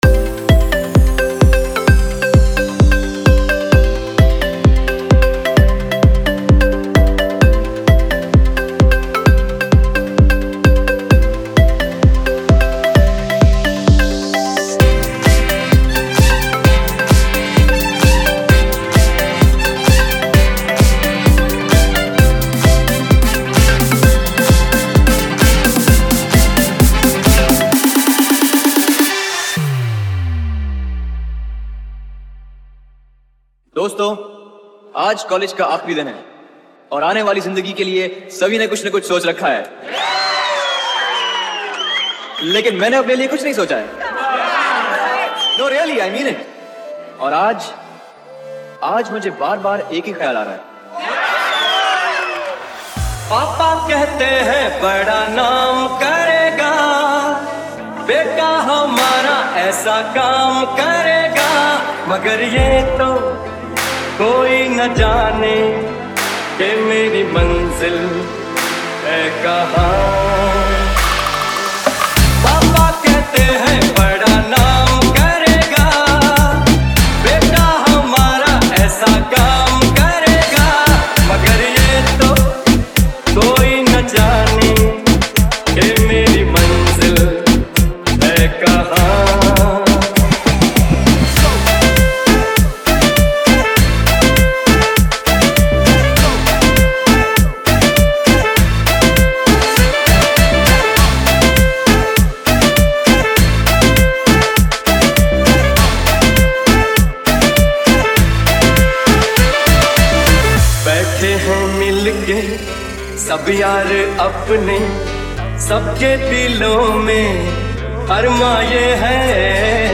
Old Hindi DJ Remix Songs